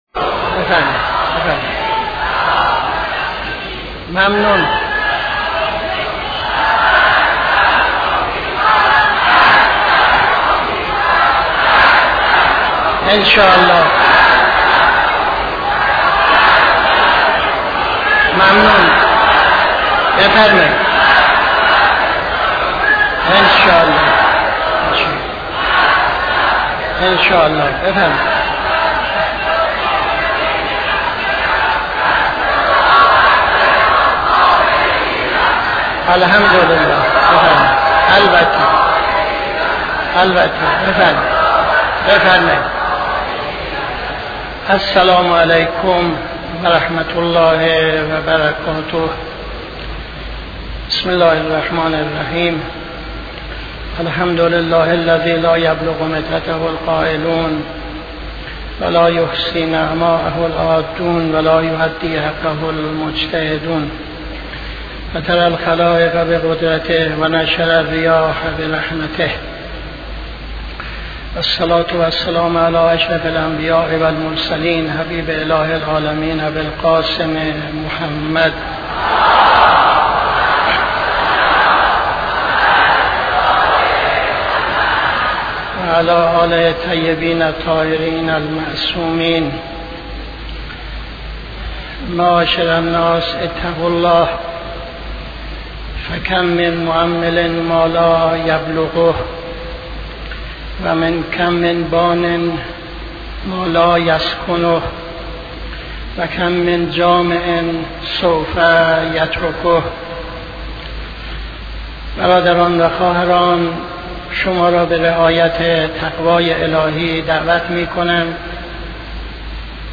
خطبه اول نماز جمعه 04-02-77